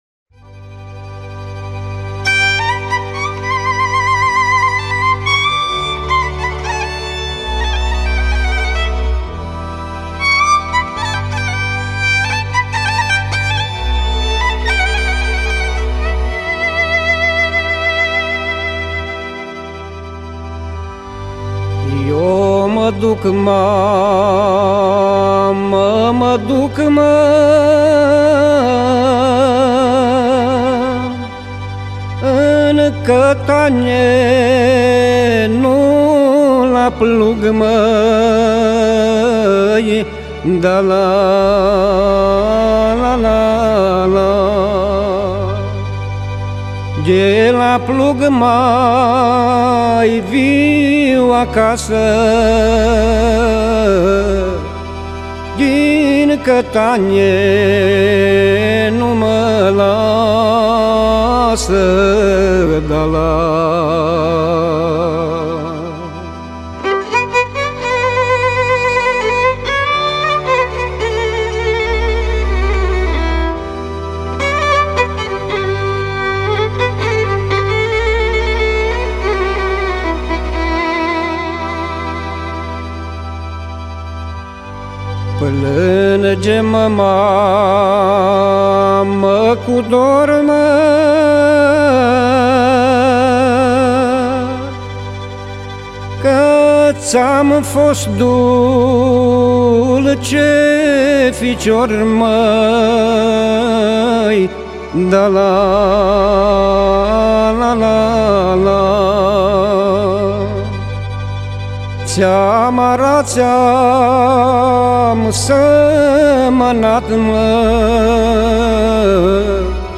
Acompaniamentul cântecelor de pe acest compact disc a fost asigurat de către Orchestra profesionistă